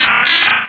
Cri de Lombre dans Pokémon Rubis et Saphir.